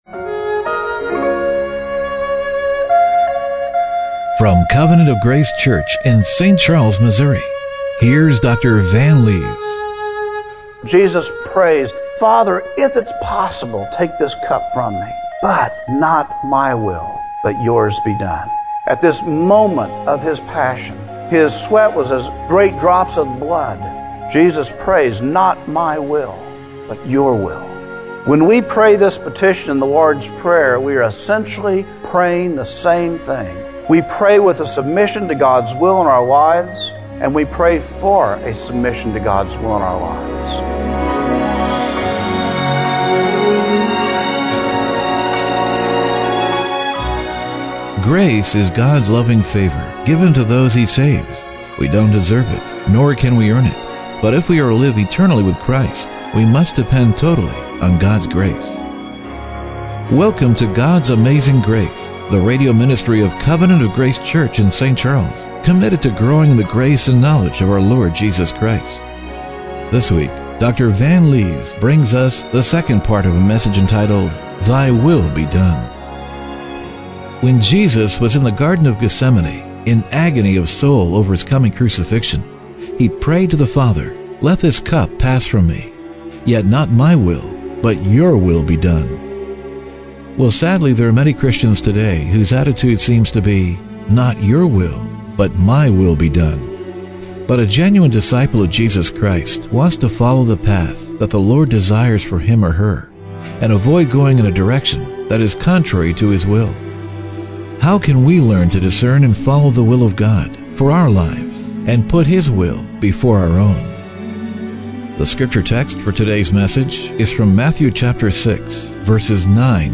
Matthew 6:7-10 Service Type: Radio Broadcast What does the Bible teach us about the Kingdom of God?